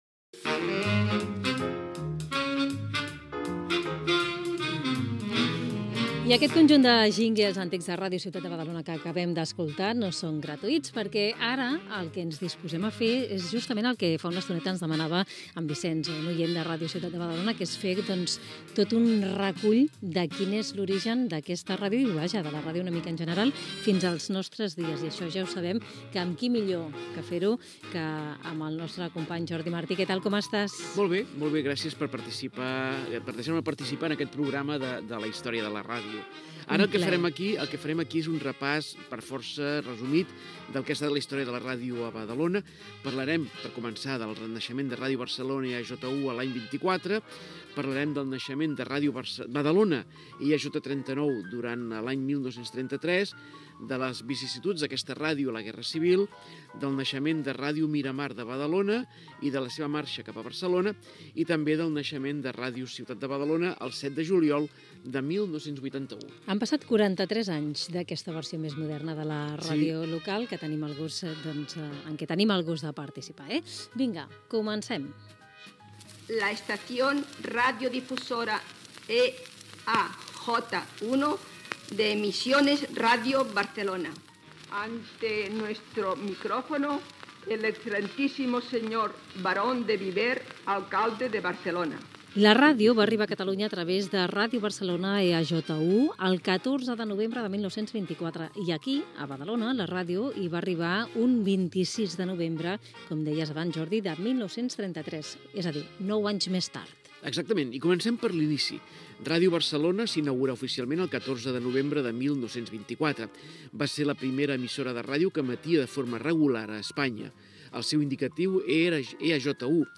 Identificació del programa, publicitat, promoció "El partit del Badalona", indicatiu de l'emissora. Entrevista
Paraules de Luis del Olmo dedicades a la ràdio.